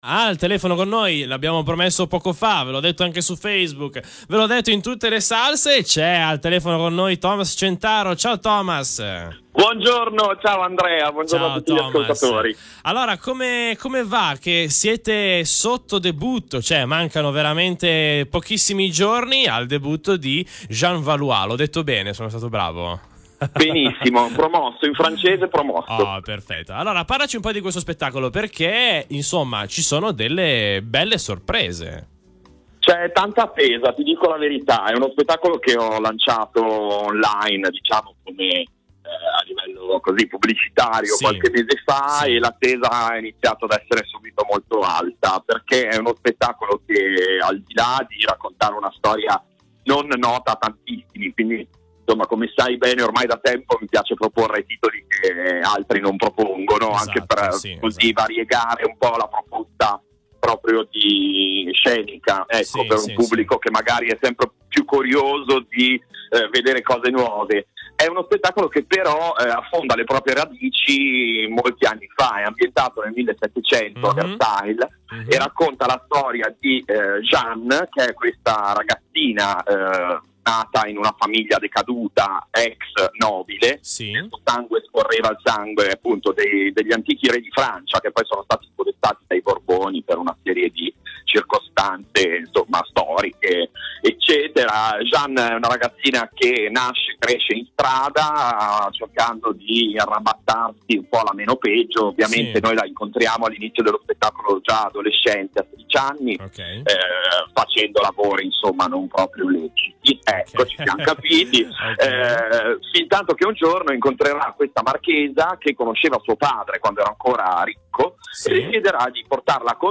Intervista a Cluster FM per “Jeanne Valois”
Intervista_ClusterFM_30nov.mp3